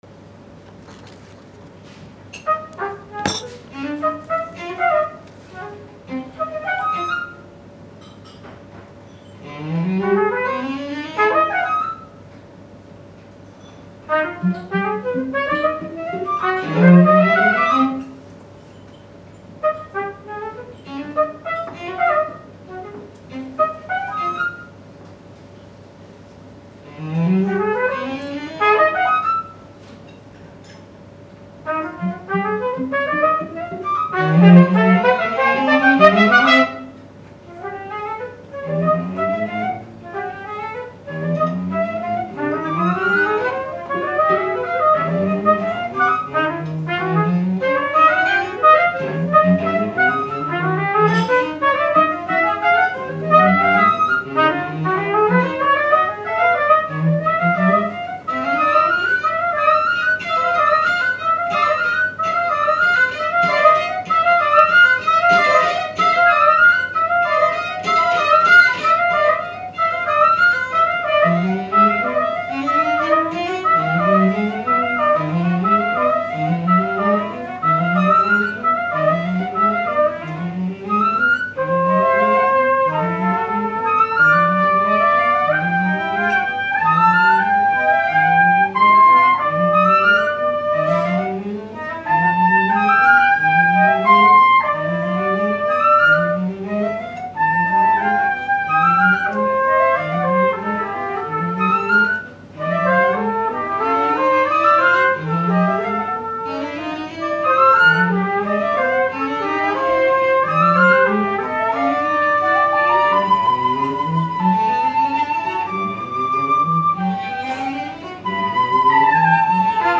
for trumpet, flute and cello